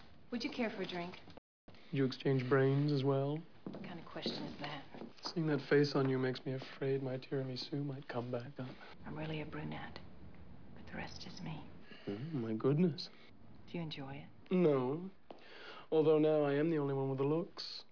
Section 2 - Mixed Conversations (3 points per movie)
For each sound in this round, we've taken one half of two different conversations from two different movies and combined them into one almost coherent conversation.